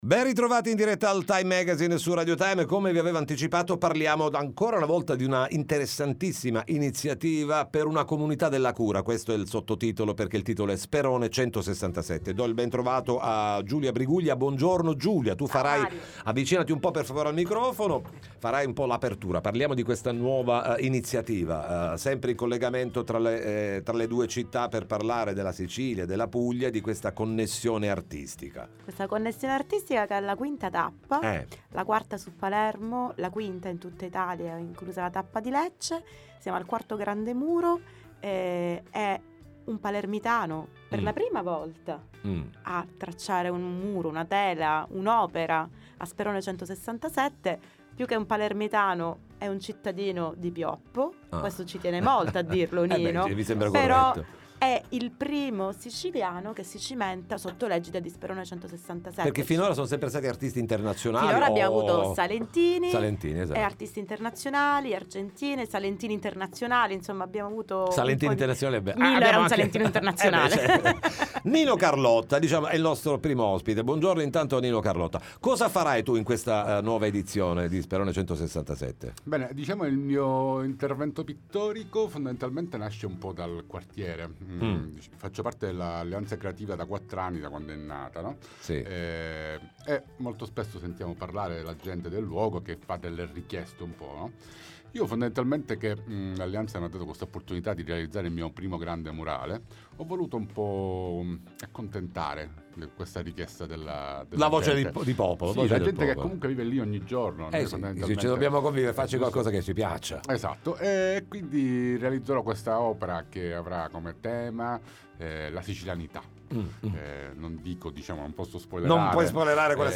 I membri di Sperone167 annunciano il nuovo intervento di arte urbana, ne parliamo con loro nei nostri studi